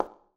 surface_felt1.mp3